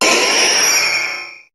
Cri de Méga-Roucarnage dans Pokémon HOME.
Cri_0018_Méga_HOME.ogg